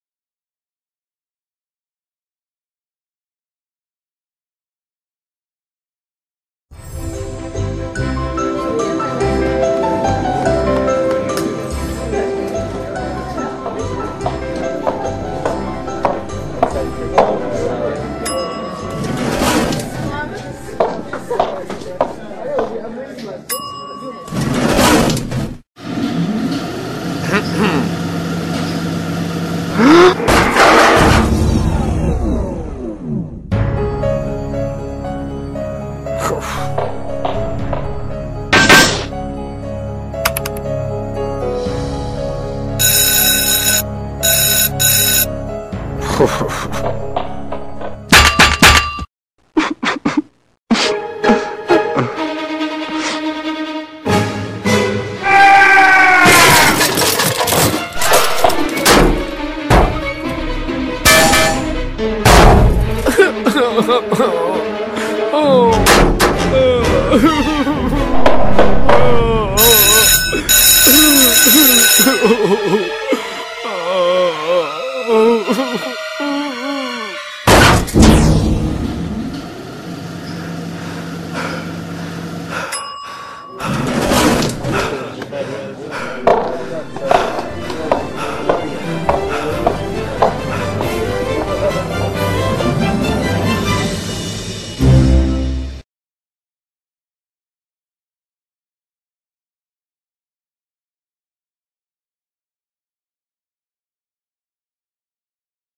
claustrofobia-ascensor.mp3